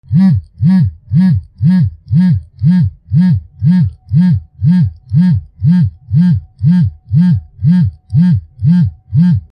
携帯電話 バイブ
ブンブンブン